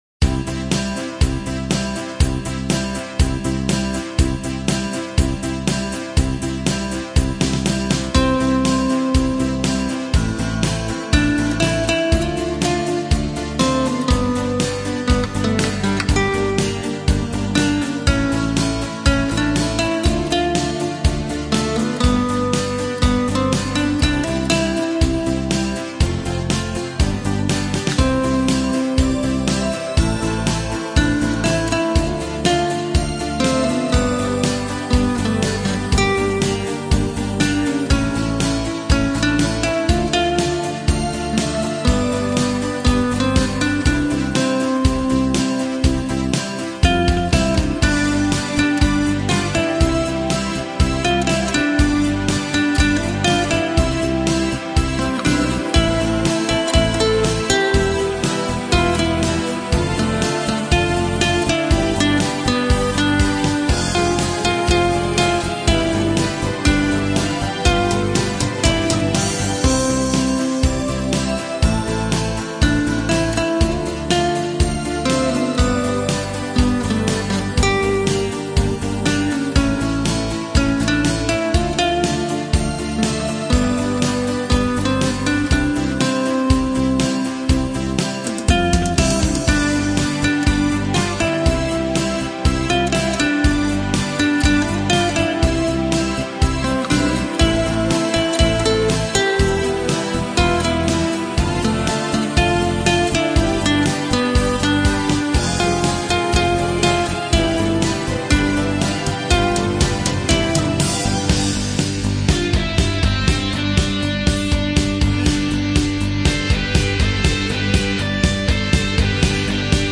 Жанр: Instrumental